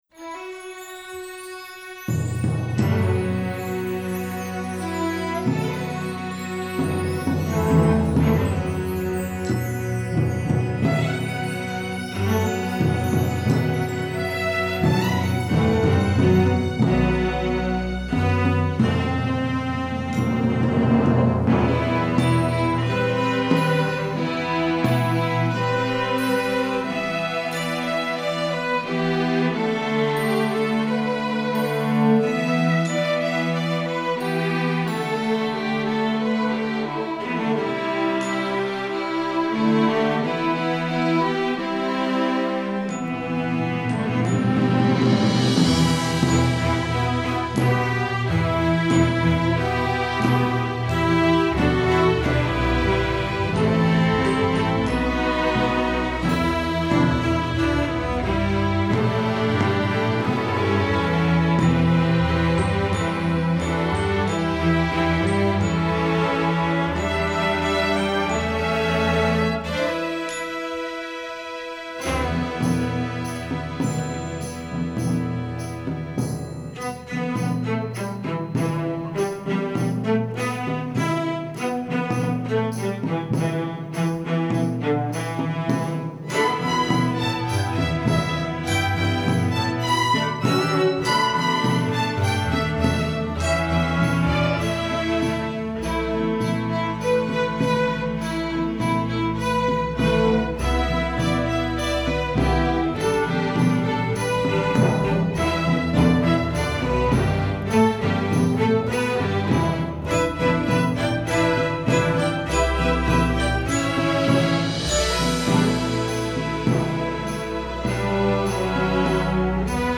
Instrumentation: string orchestra
traditional, instructional